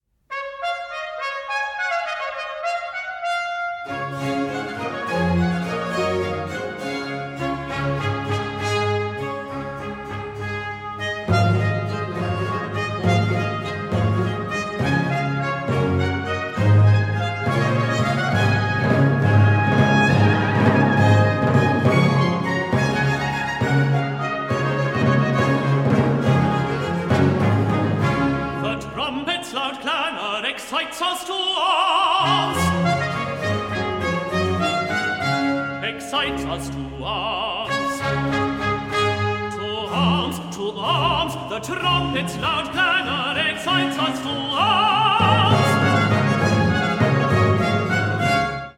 tenor air and chorus